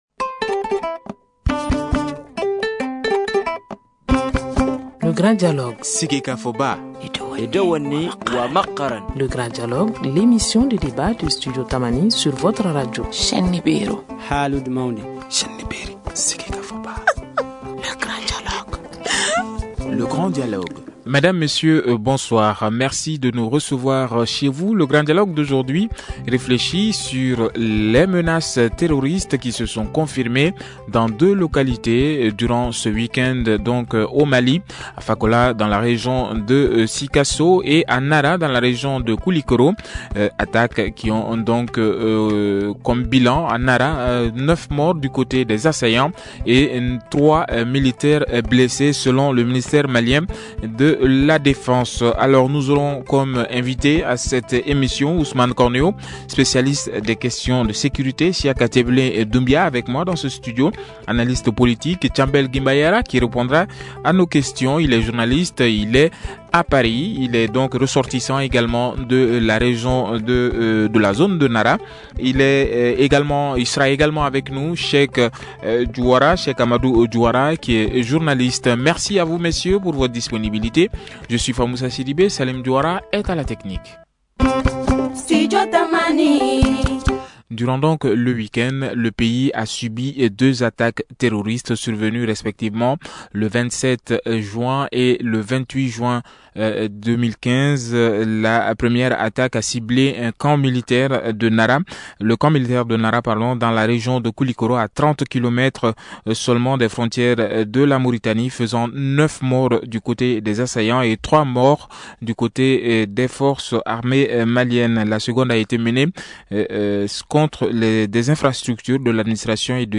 Voici les axes de ce débat : Le point sur les attaques; L’efficacité de la lutte anti terroriste au Mali; L’armée malienne doit-elle changer de stratégie de lutte ?